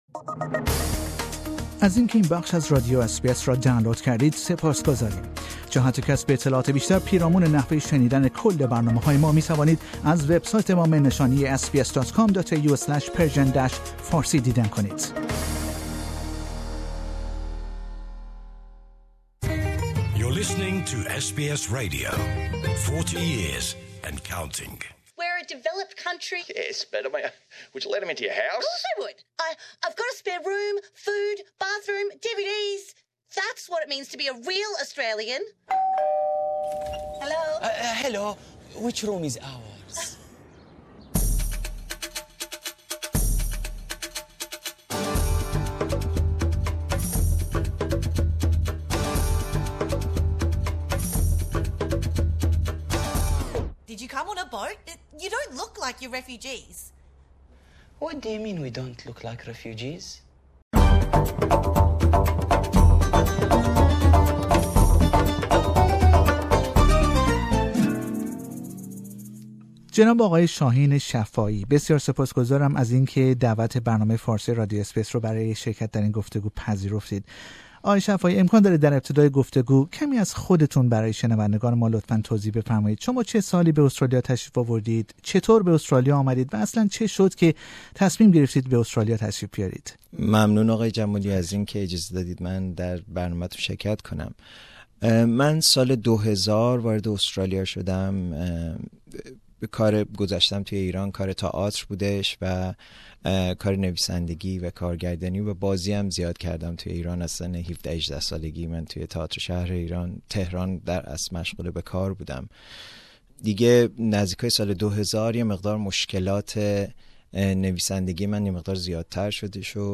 در گفتگویی که با او داشتیم به این مجموعه اینترنتی جدید پرداختیم.